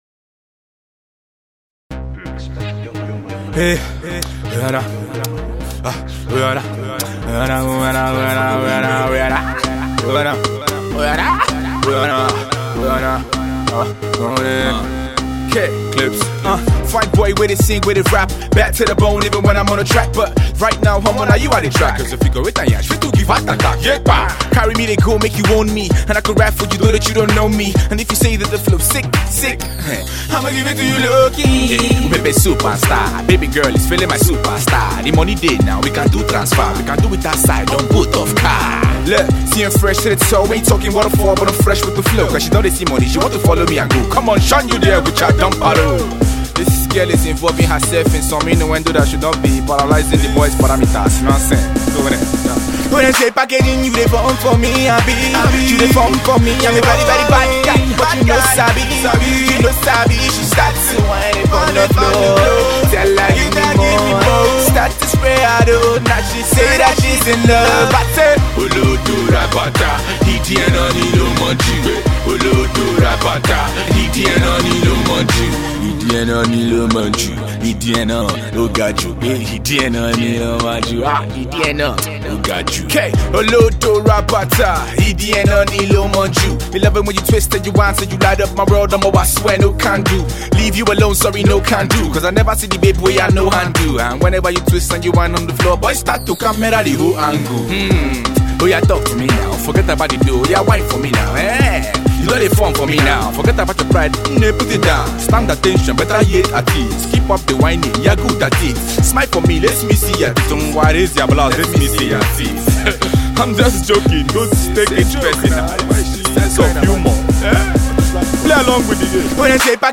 and laced with some heavy synth beats